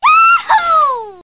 One of Princess Daisy's voice clips in Mario Kart DS